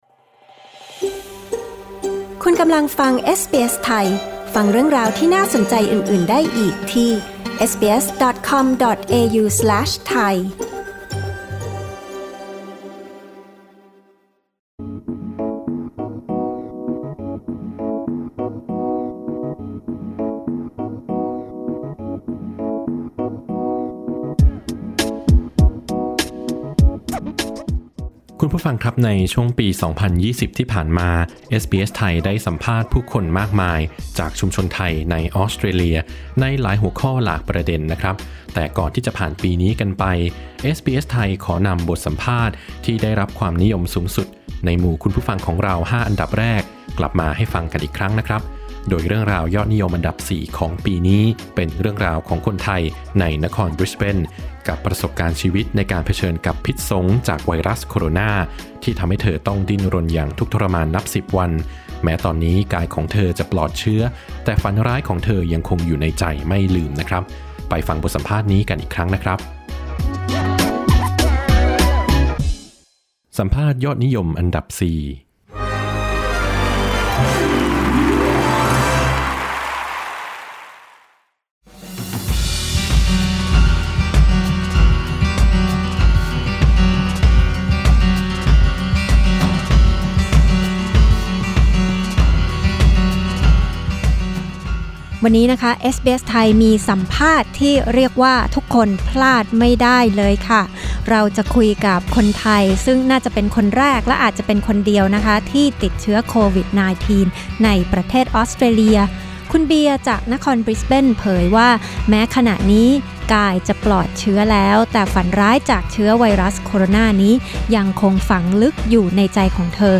สัมภาษณ์ยอดนิยมอันดับ 4 ประจำปี 2020